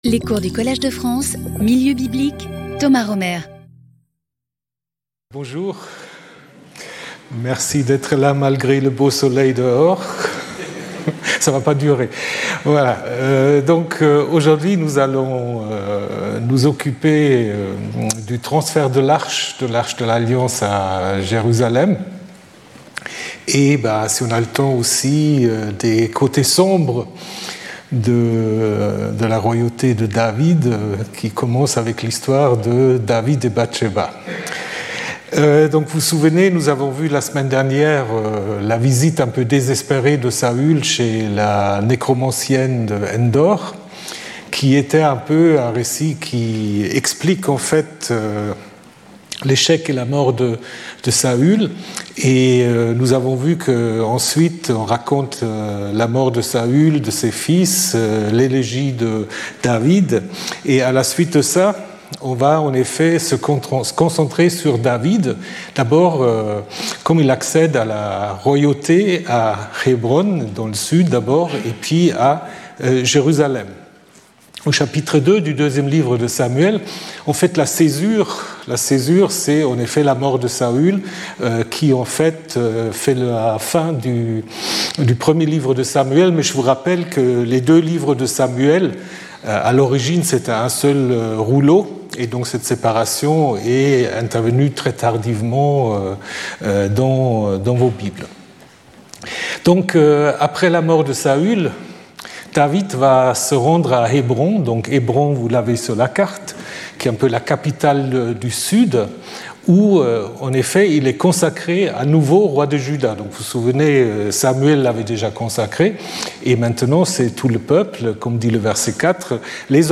Skip youtube video player Listen to audio Download audio Audio recording Documents and media Download support pdf (5.64 MB) Abstract As soon as the tribe of Judah rallied to David and he chose Jerusalem as his stronghold, he had the ark of Yhwh brought from Qiryath-Yearim to symbolize Yhwh's presence in Jerusalem. The transfer of the ark is fraught with difficulties, and curiously marks a break in David's relationship with his wife Mikal. Speaker(s) Thomas Römer Professor and Administrator of the Collège de France Events Previous Lecture 12 Feb 2026 14:00 to 15:00 Thomas Römer Saul, David, Solomon: mythical or historical figures?